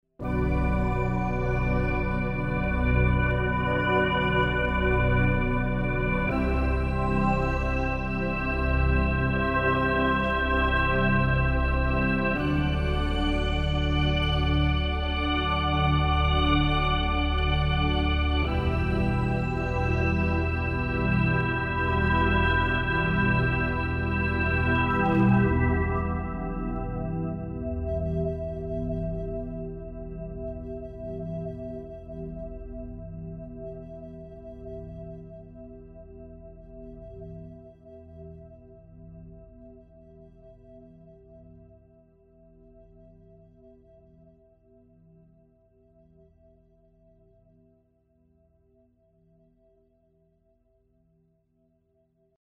Class: Synth module
ethereal chords